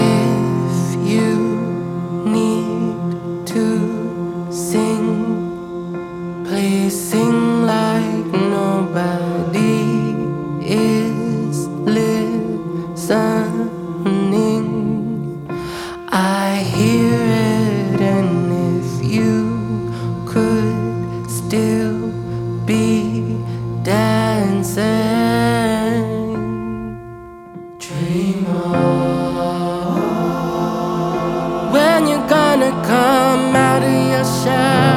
Жанр: Поп / Инди / Альтернатива